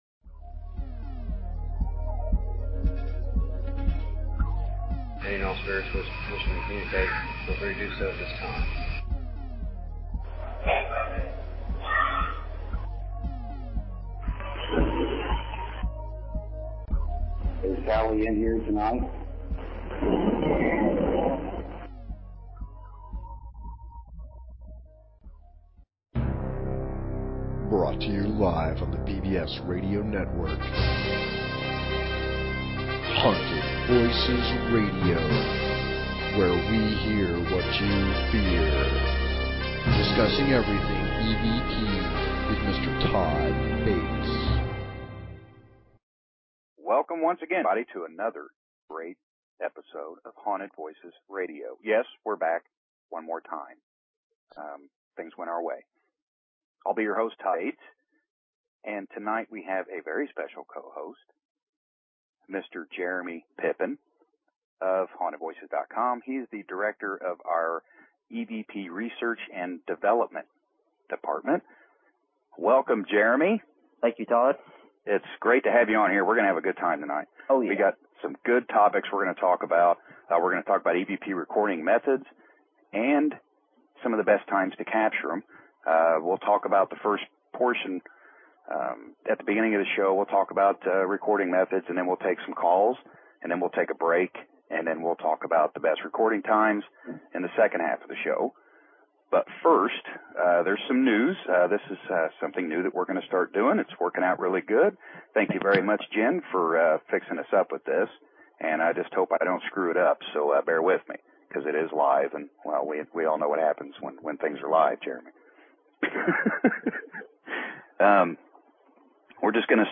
Talk Show Episode, Audio Podcast, Haunted_Voices and Courtesy of BBS Radio on , show guests , about , categorized as